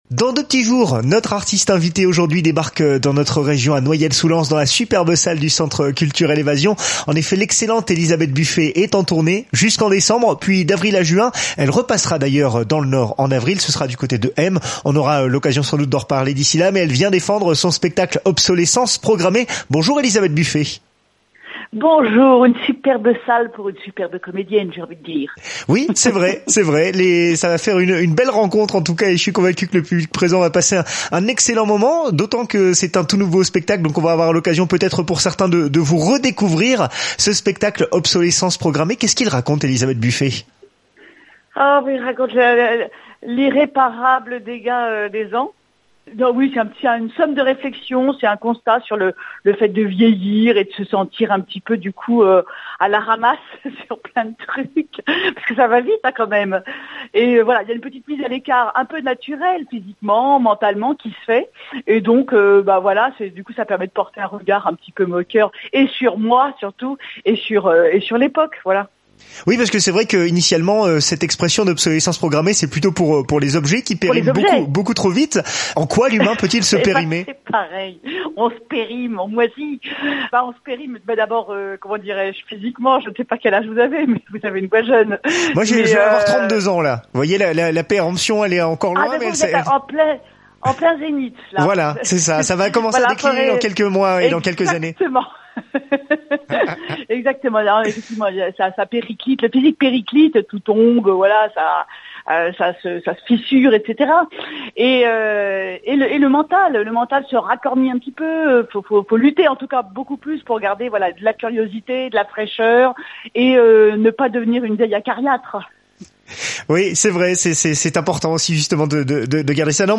Une interview tout en confidence et en humour avec le charme d’Elisabeth Buffet qui cohabite avec son franc-parler, pour des moments très drôles dans cet entretien!